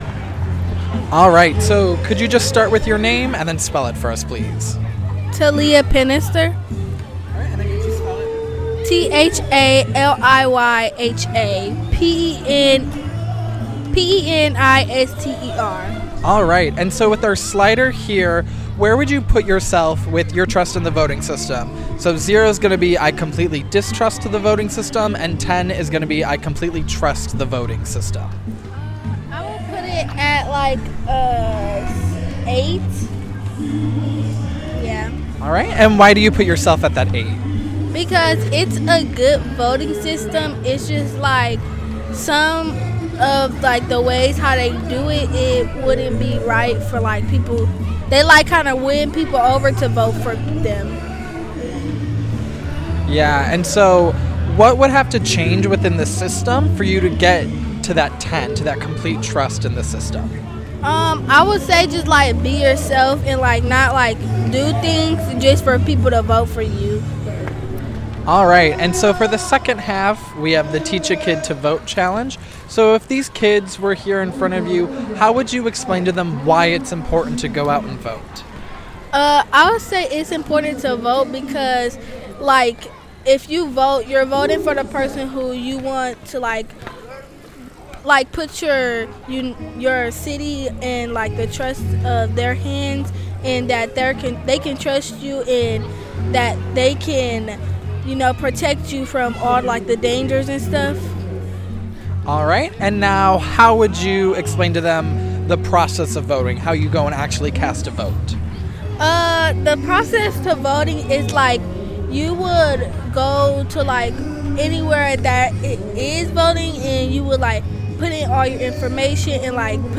Rooted and Rising Block Party